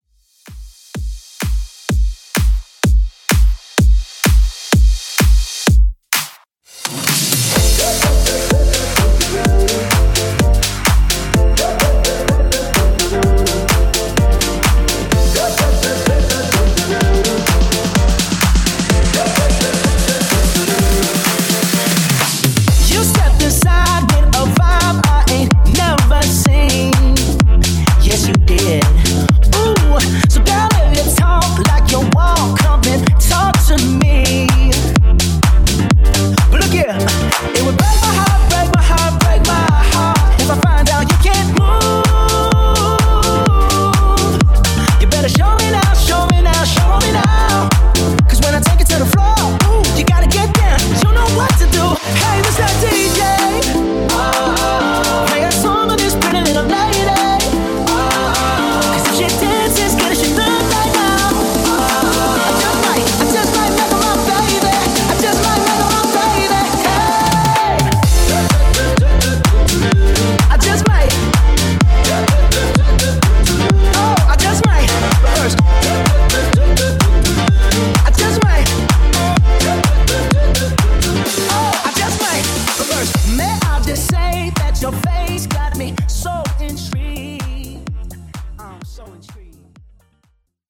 No. 82 in DANCE
Genre: Version: BPM: 127 Time: 3:05